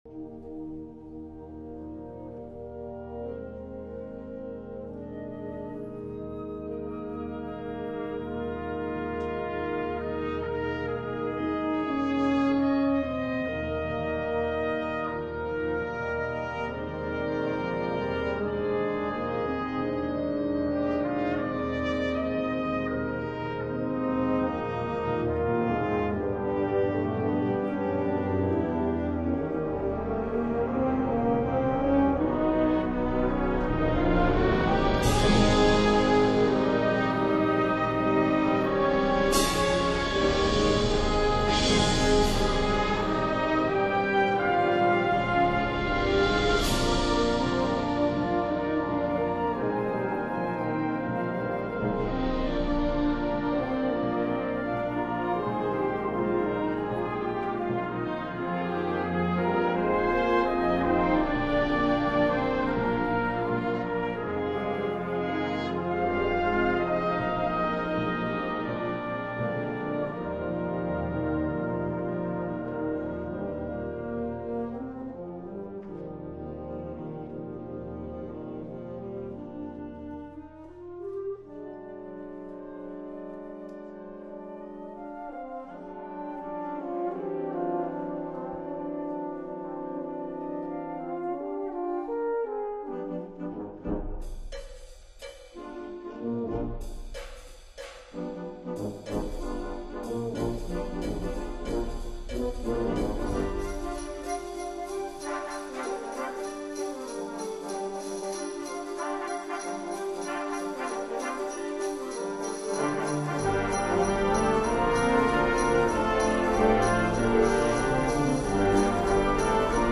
編成：吹奏楽
Sand Block
Wash Board
Cow Bell